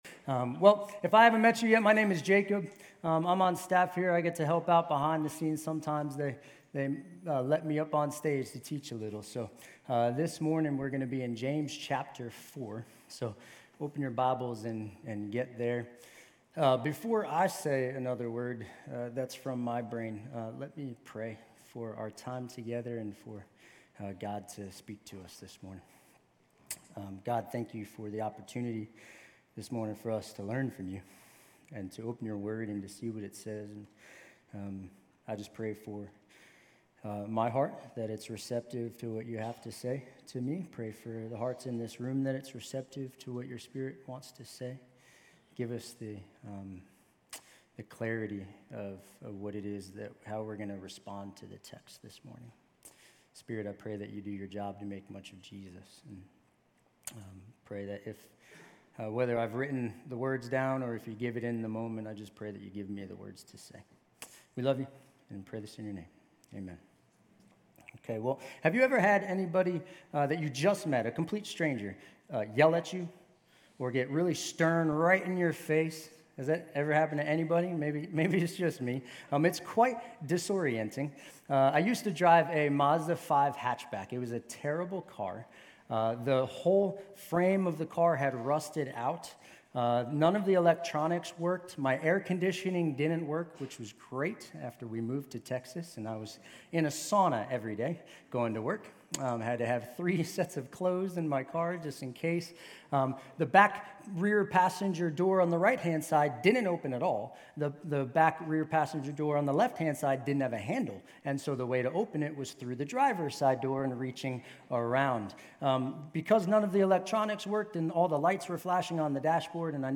GCC-UB-July-23-Sermon.mp3